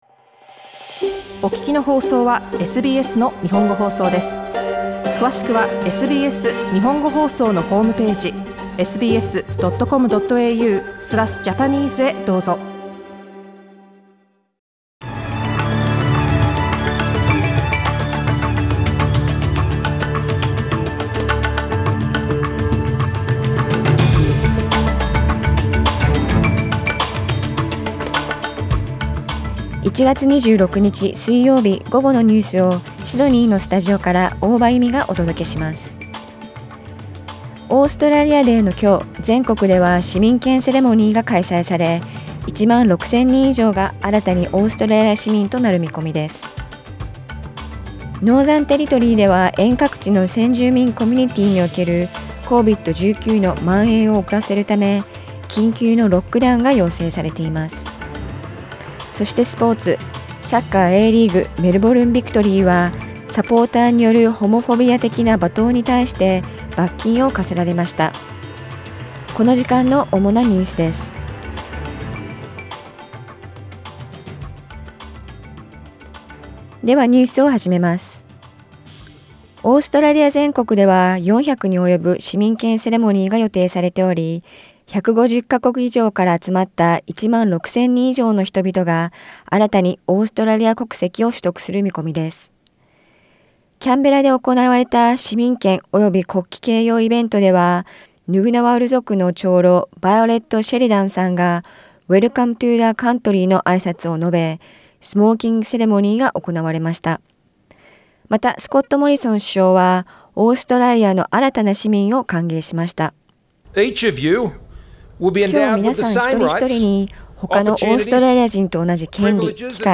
1月26日午後のニュース
Afternoon news in Japanese, 26 January 2022